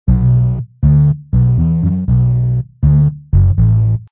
popTwo_bass00.mp3